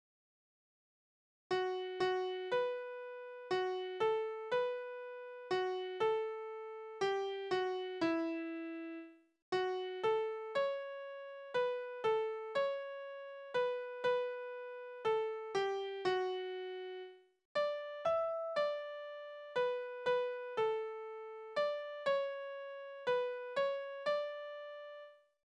Balladen: Der gerettete Matrose
Tonart: D-Dur
Tonumfang: große Sexte
Besetzung: vokal